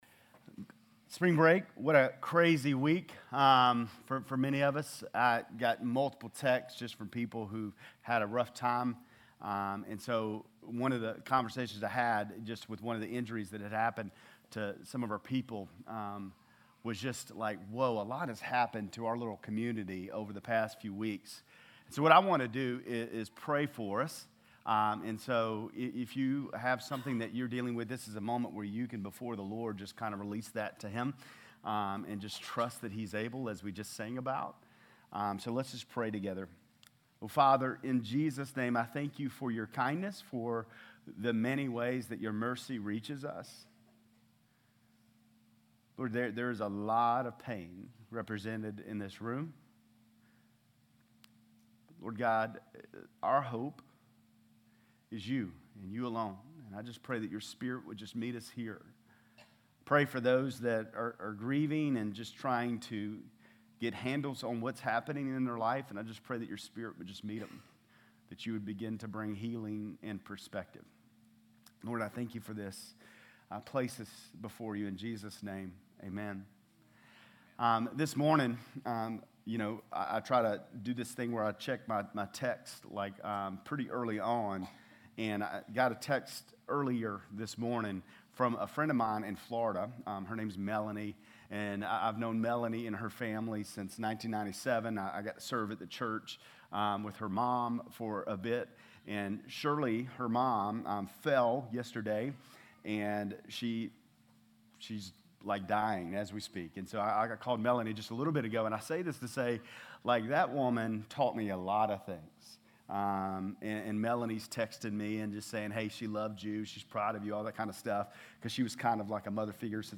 Lindale-March-19-Sermon.mp3